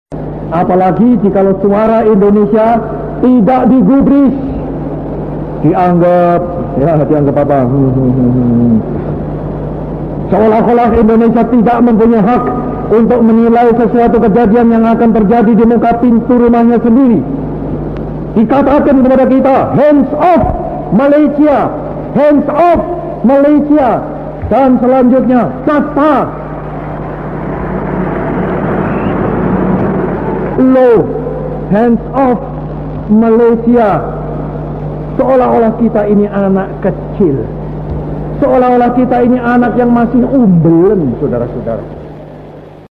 수카르노 대통령의 1963년 인도네시아 혁명 기념일 연설 발췌